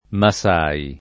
masai [ ma S# i ] o massai